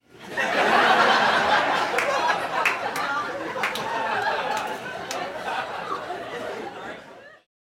Звук веселой публики